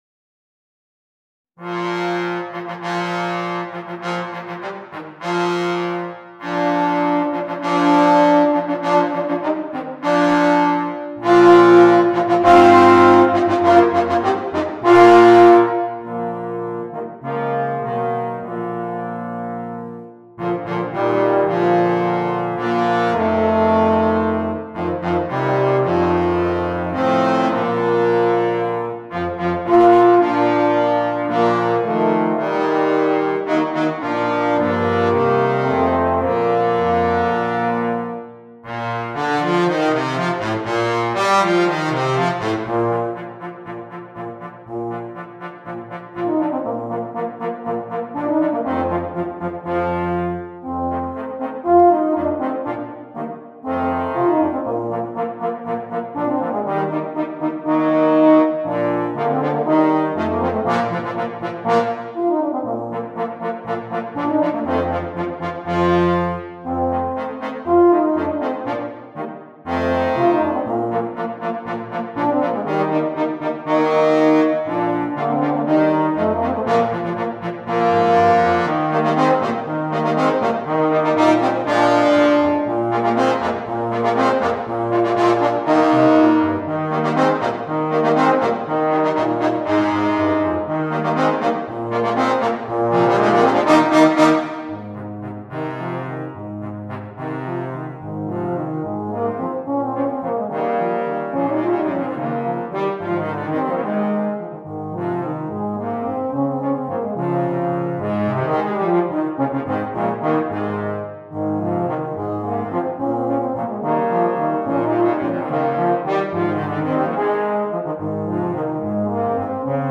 Voicing: Euphonium Quartet